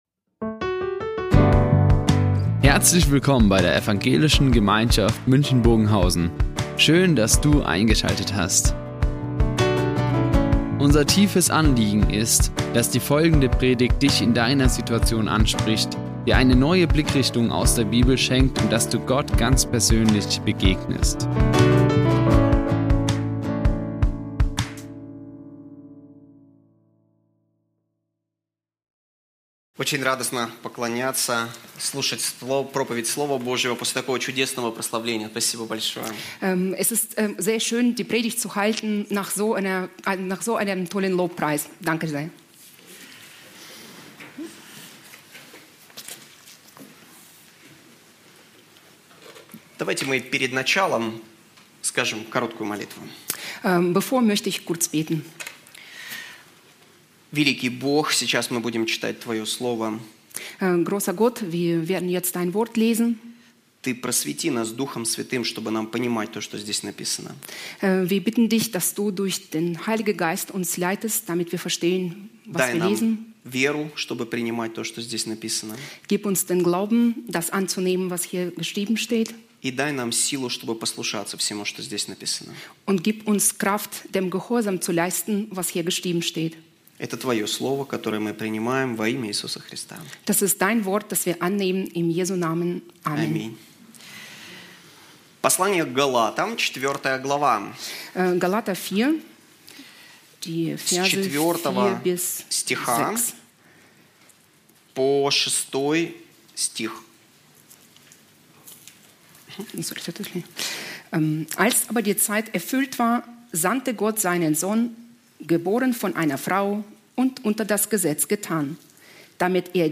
Der Dreieinige Plan | Predigt Galater 4, 4-6 ~ Ev.
Die Aufzeichnung erfolgte im Rahmen eines Livestreams.